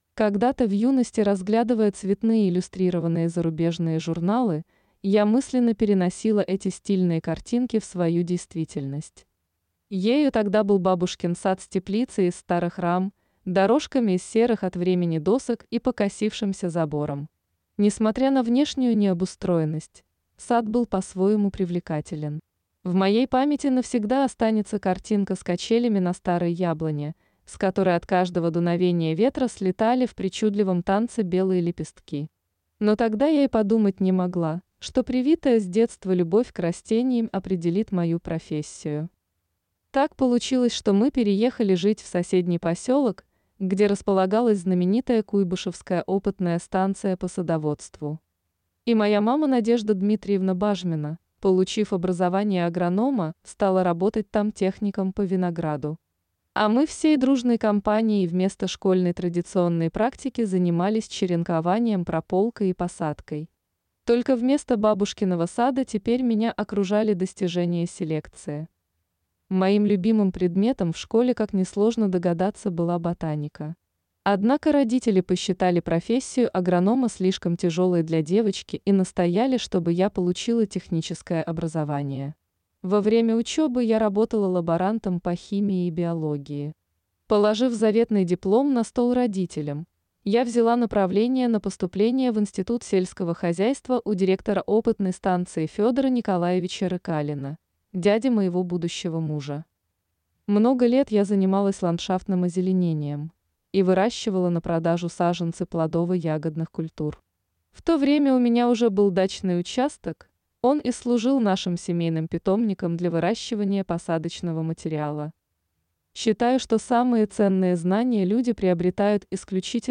Аудиокнига Больше, чем 5 соток. Как на маленьком участке получить максимум урожая | Библиотека аудиокниг